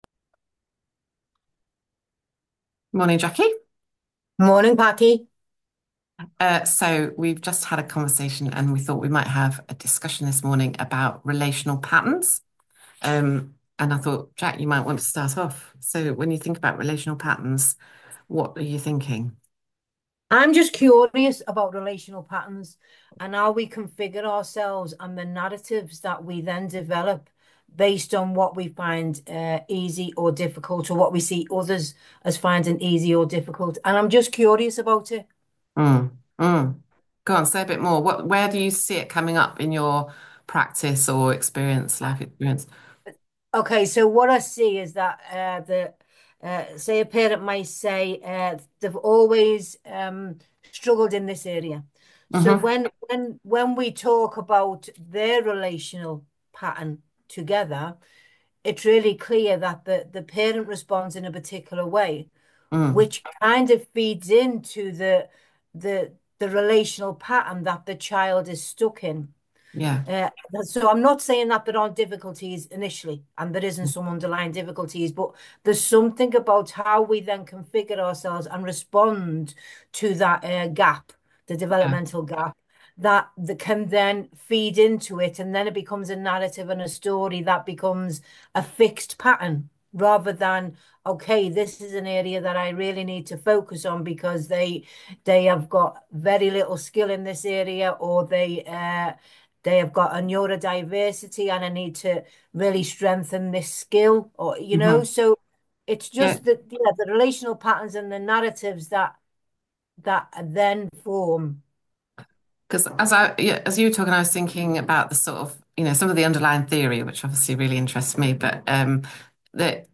2.3 For All: A Conversation About Relational Patterns